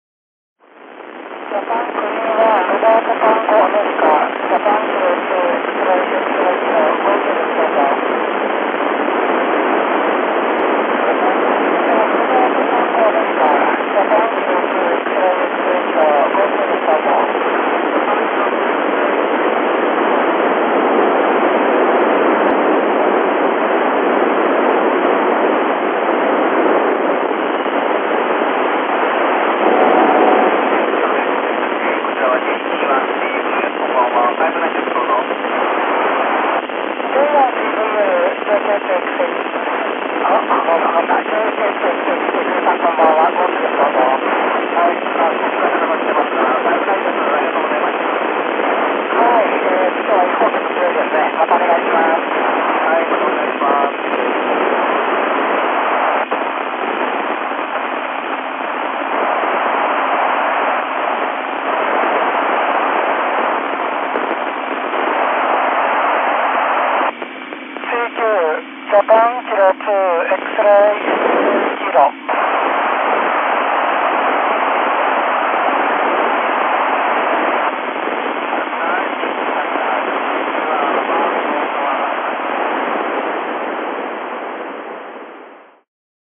衛星の軌道は当局の苦手な東側を、隣の棟の陰に入りながらでしたので、受信状況はイマイチ。平日でもあったので局も少なく、まあこんなものでしょうか。